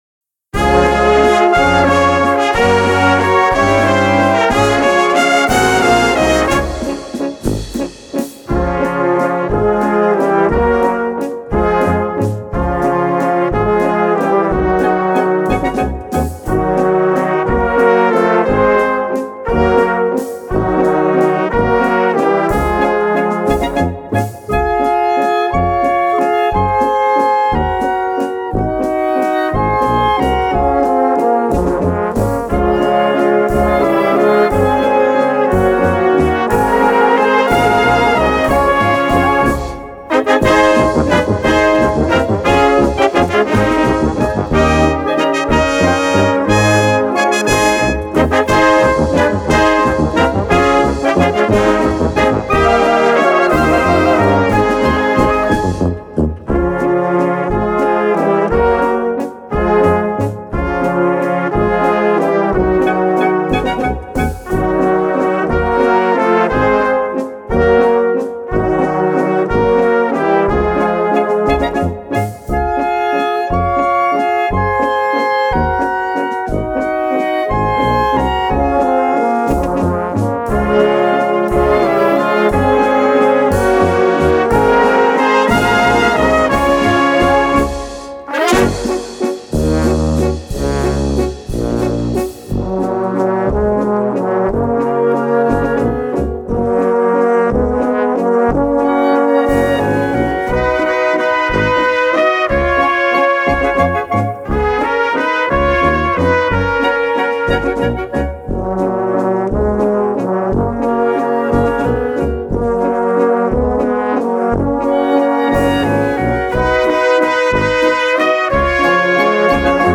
Kategorie Blasorchester/HaFaBra
Unterkategorie Walzer
Besetzung Ha (Blasorchester)